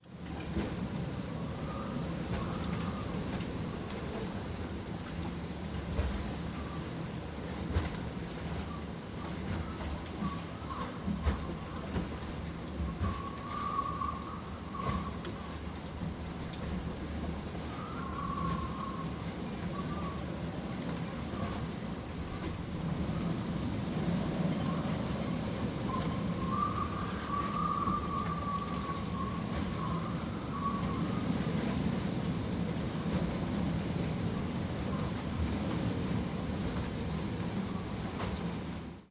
Звуки плохой погоды
Громовые раскаты за окном частного дома